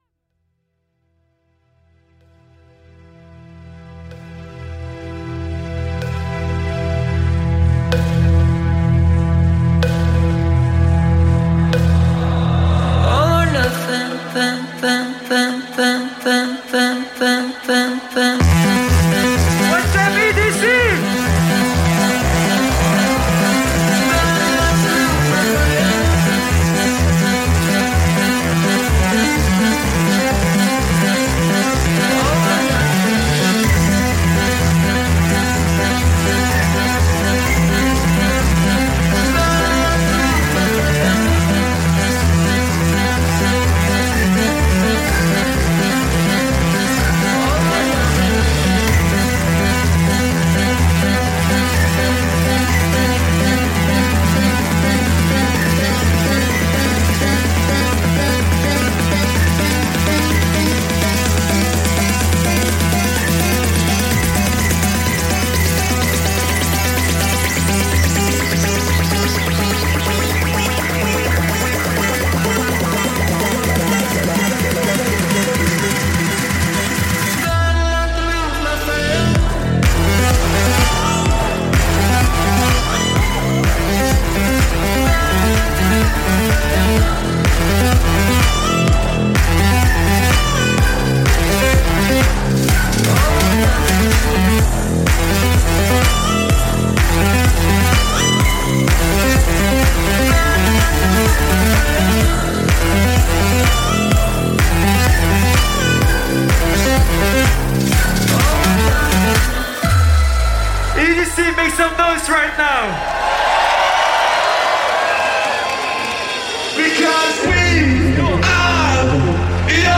Liveset/DJ mix